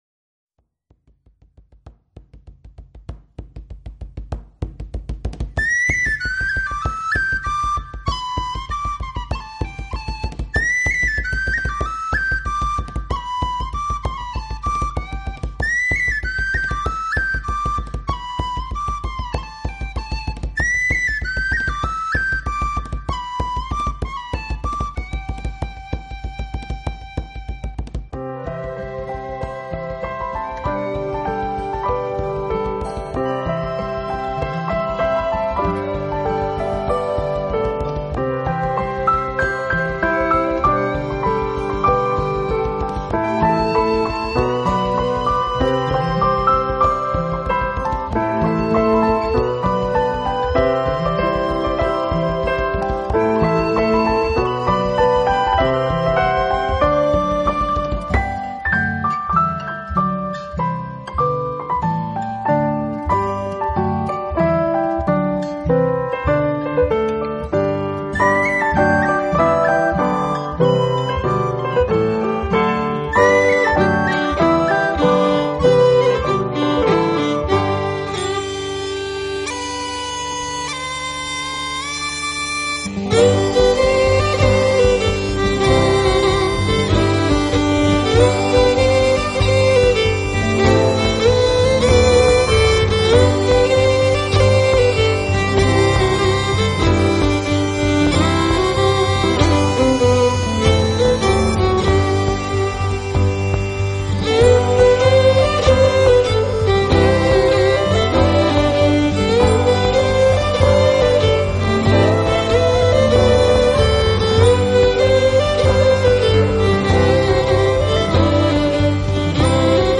歌曲，音乐包括有钢琴独奏、传统的Celtic民谣、二重唱、独唱。
Celtic及New Age去演绎，清新动听， 音色甜美，有轻快的舞曲节奏，有醉人的